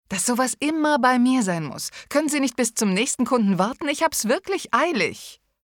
Ein altersloser Alt, von seriös bis sinnlich.
Sprechprobe: eLearning (Muttersprache):
A smooth voice with a touch of authority.